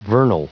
Prononciation du mot vernal en anglais (fichier audio)